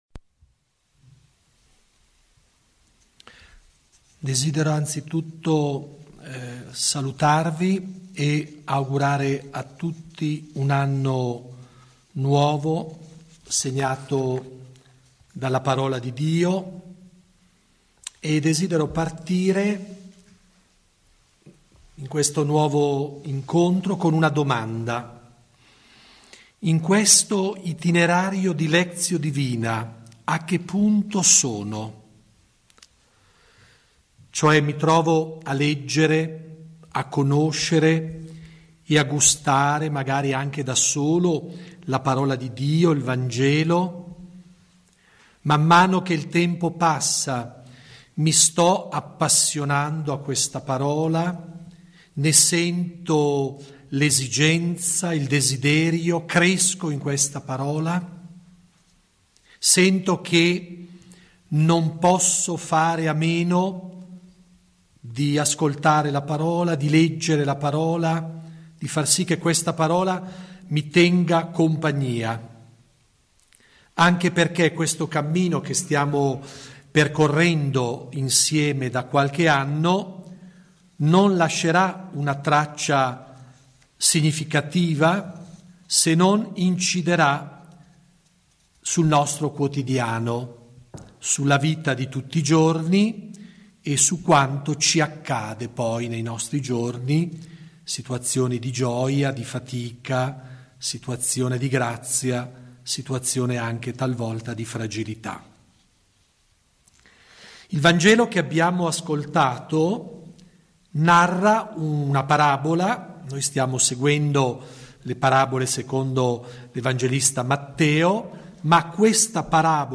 3° incontro «RICEVERE SENZA MERITARE» (Mt 20, 1-16) Domenica 14 gennaio 2018 ore 16.00 | Lectio divina a Giussano, S. Francesco Lunedì 15 gennaio 2018 ore 21.00 | Lectio divina a Paina, S. Margherita